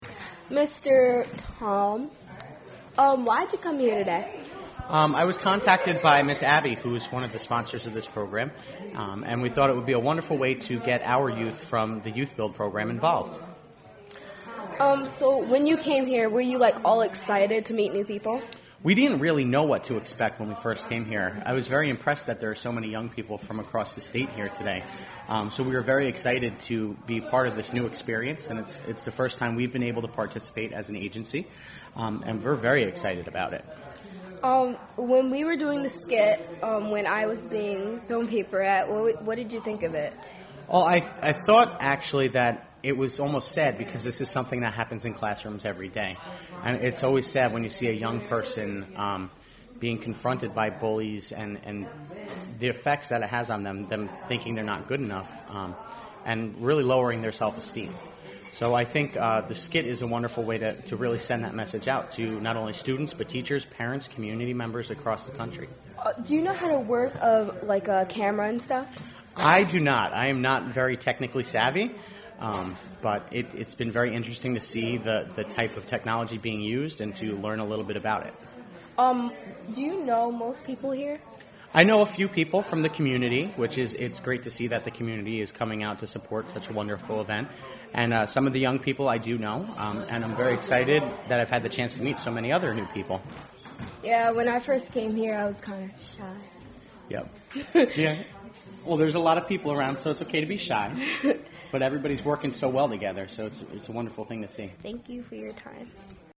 NY Shout Out!: Youth Media Production Workshop and Festival: Apr 28, 2012: 12pm- 10pm
Interview
at the NY Shout Out! youth media workshop and festival at the Sanctuary for Independent Media in Troy, NY.